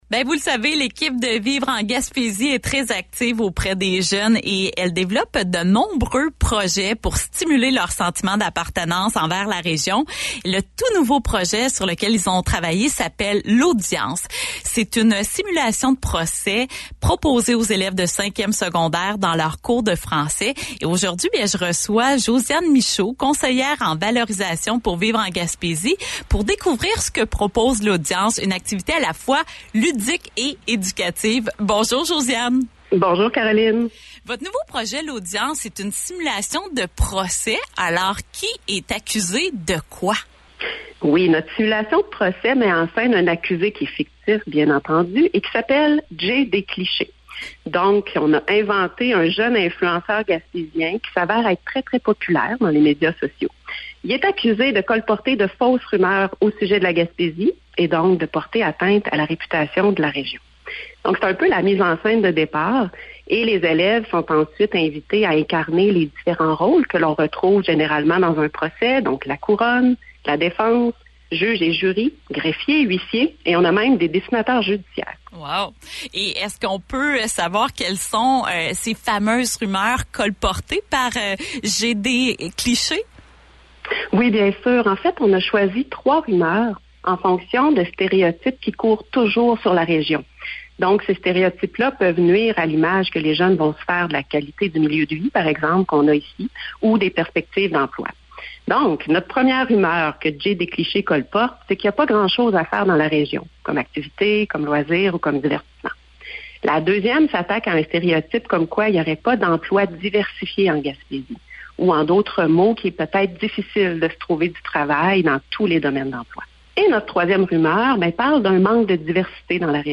Une simulation de procès pour contrer les stéréotypes sur la Gaspésie - Radio-Gaspésie